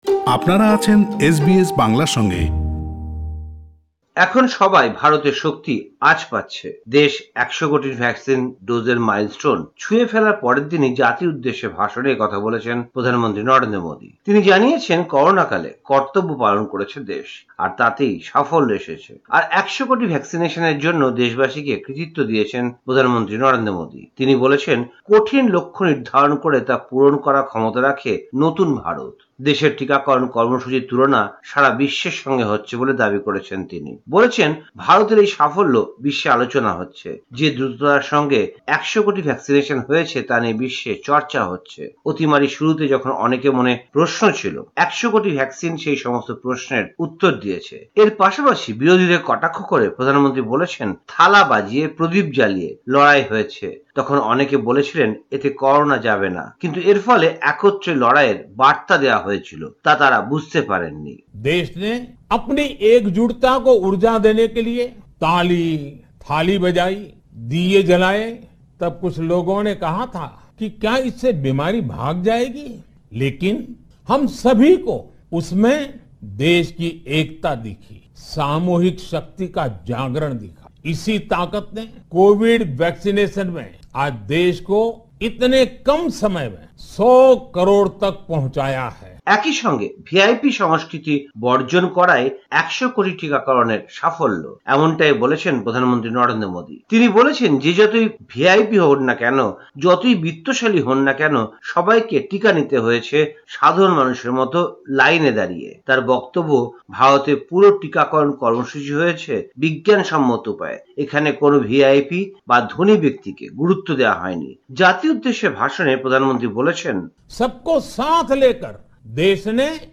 ভারতীয় সংবাদ: ২৫ অক্টোবর ২০২১